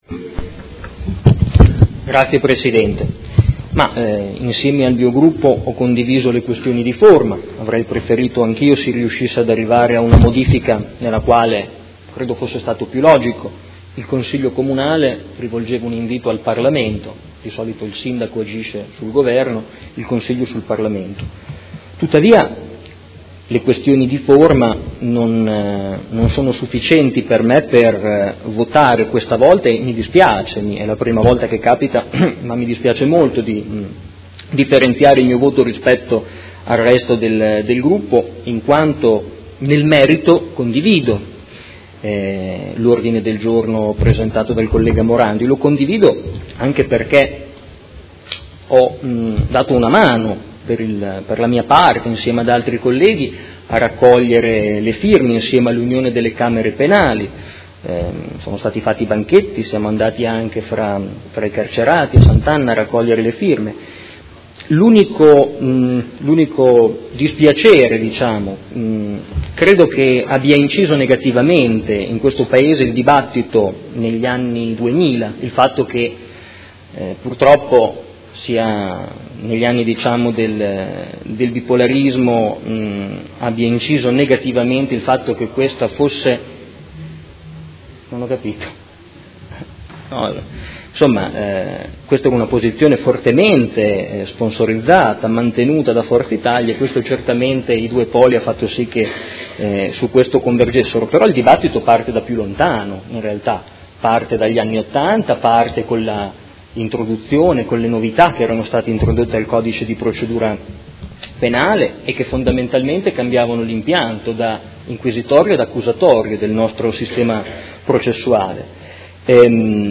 Marco Forghieri — Sito Audio Consiglio Comunale
Seduta del 9/11/2017. Dibattito su Ordine del Giorno del Gruppo Forza Italia avente per oggetto: La separazione delle carriere all’interno della Magistratura: una necessità non più procrastinabile per il nostro ordinamento di giustizia